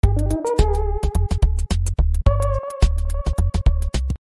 • Качество: 320, Stereo
интригующие
загадочные
демотивирующие